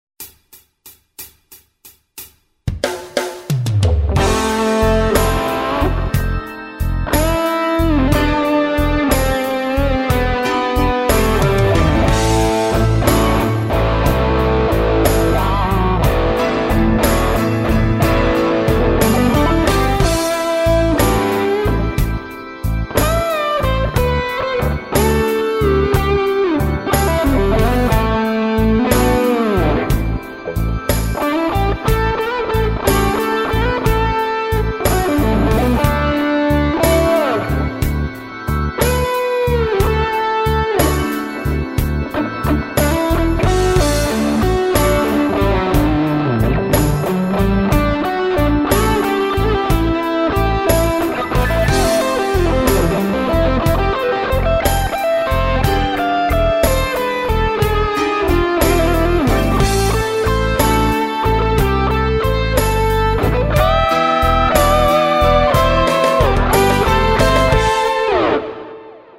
no bolo tam par nepresnosti,ale to mi az tak nevadilo..vadilo mi,ze som nepocul jedine vibrato...velka chyba Žmurk
Pekne, prijemny zvuk.
imblues.mp3